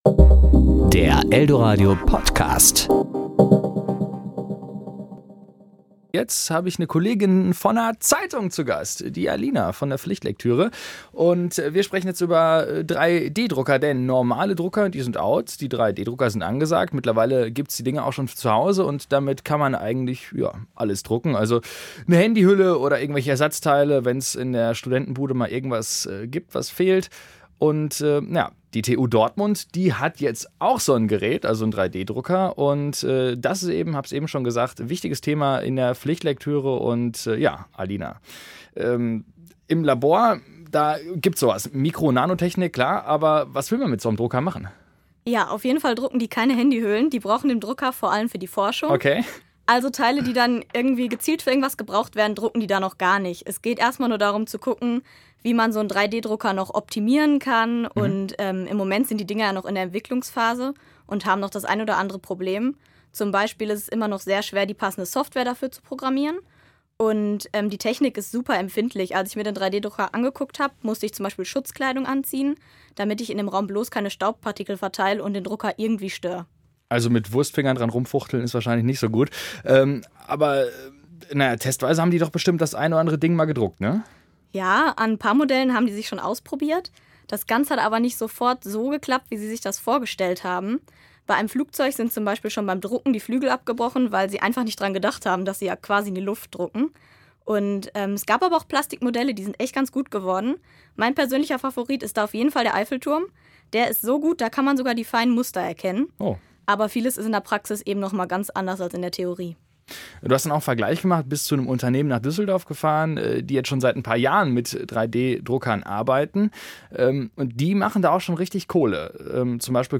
Kollegengespräch  Ressort